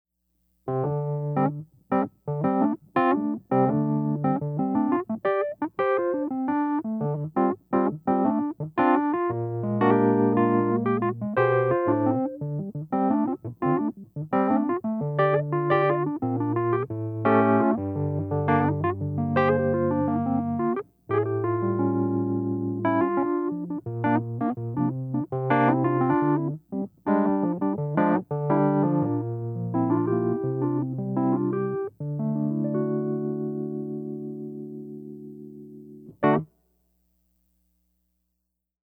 Wurlitzer 106P Groove:
106P-groove.mp3